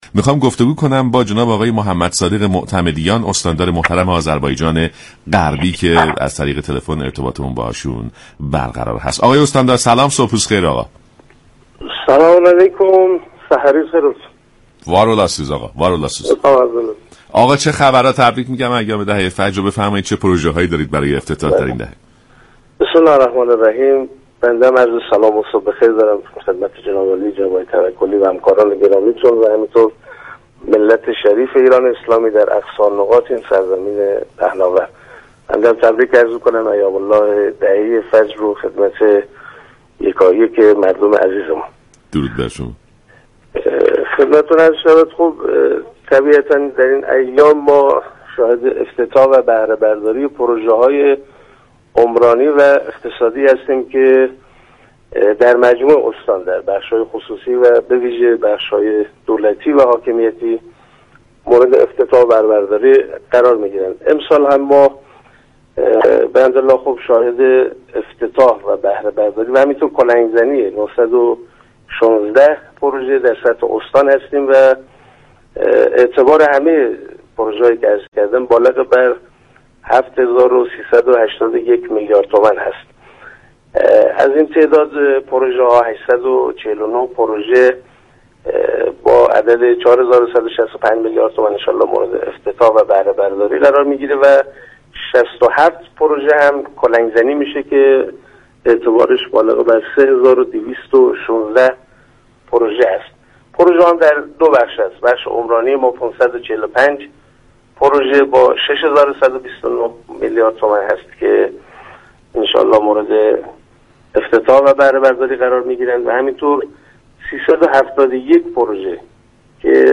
استاندار آذربایجان غربی در برنامه «صبح انقلاب» گفت: امسال استان آذربایجان غربی، شاهد افتتاح، بهره برداری و كلنگ زنی 916 طرح است.